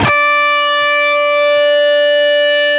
LEAD D 4 CUT.wav